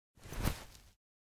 检查血迹.ogg